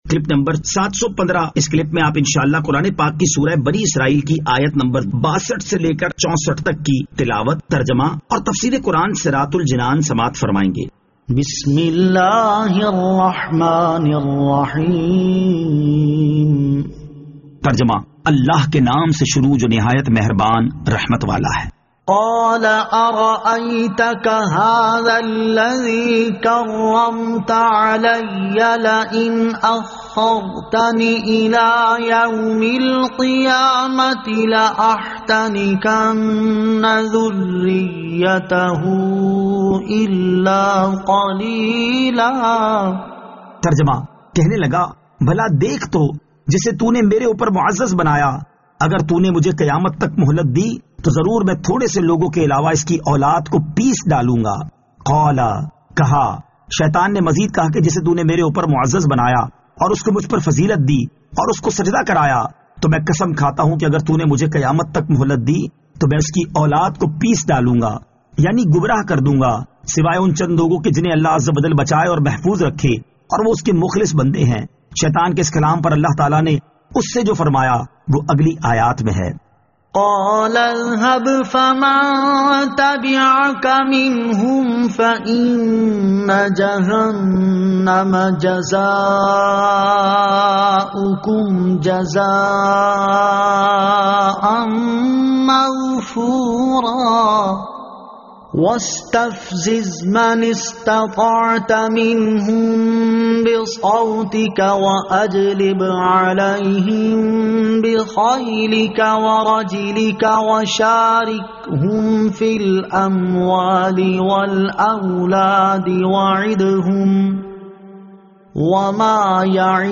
سُوَّرۃُ الاسٗرَاء آیت 62 تا 64 تلاوت ، ترجمہ ، تفسیر ۔